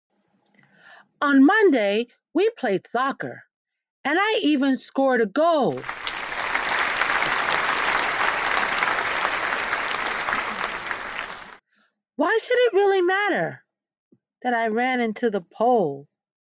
Story telling from enthusiastic teachers.